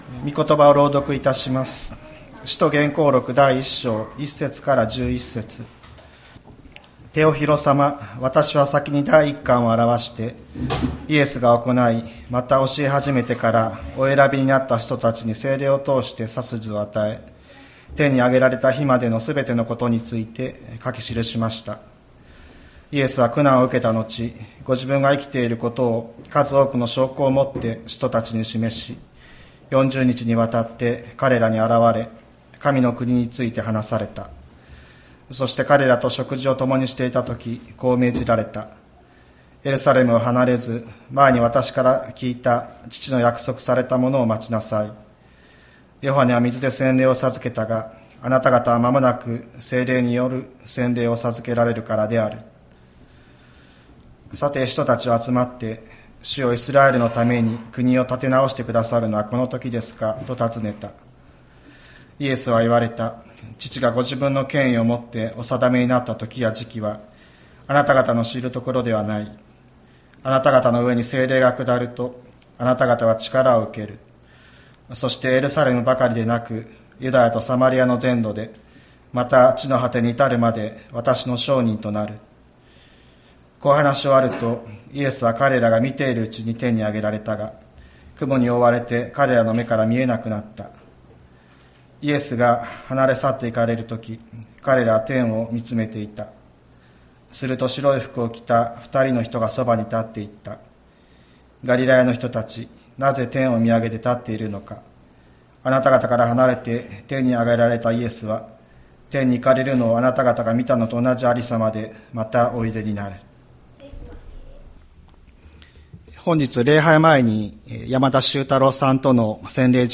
2024年04月28日朝の礼拝「キリストは今どこに」吹田市千里山のキリスト教会
千里山教会 2024年04月28日の礼拝メッセージ。